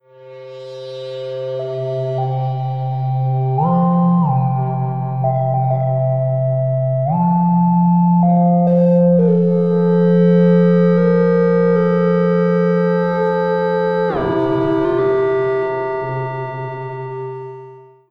In the audio example I'm using two PrimeLimitConformers to perform the tune using two knobs on a midi controller.